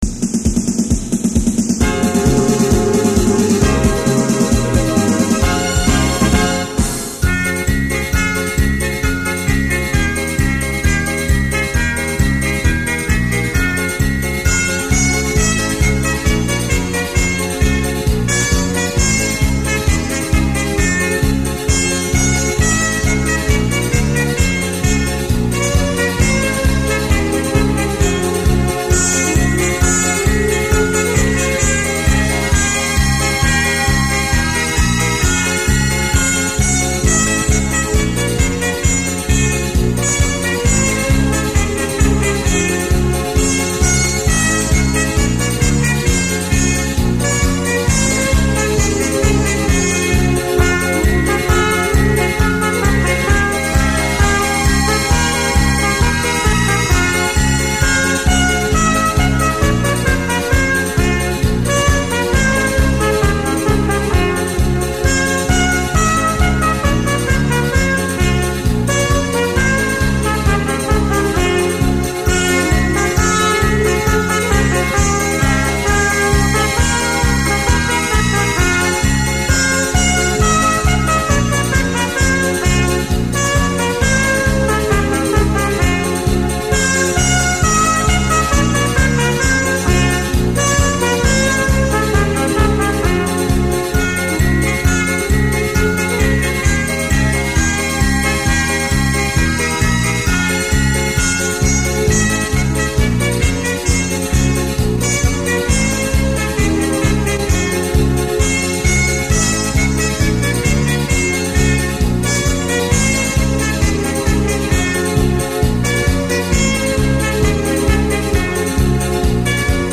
小号独奏：
本辑就是根据耳孰能详的俄罗斯经典民歌 改编的小号音乐,曲调优美动听，十分感人。